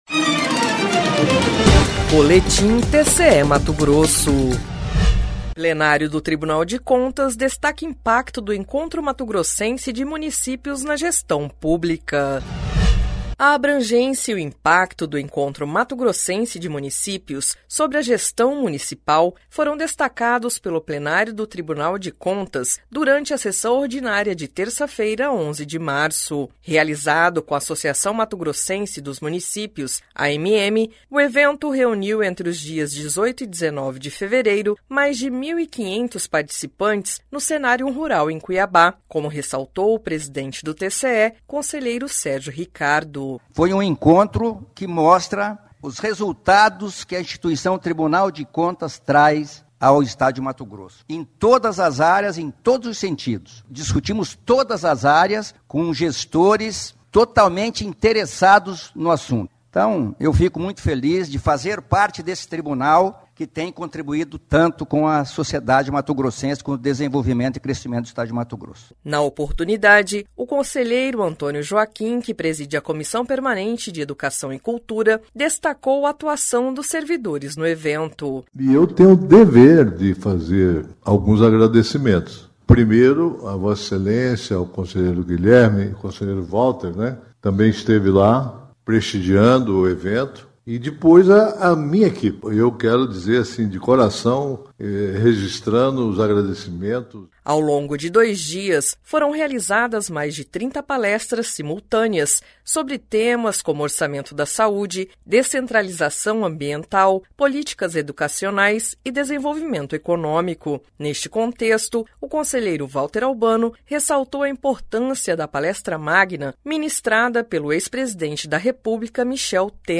Sonora: Sérgio Ricardo – conselheiro-presidente do TCE-MT
Sonora: Antonio Joaquim – conselheiro presidente da COPEC do TCE-MT
Sonora: Valter Albano – conselheiro do TCE-MT
Sonora: Campos Neto - conselheiro do TCE-MT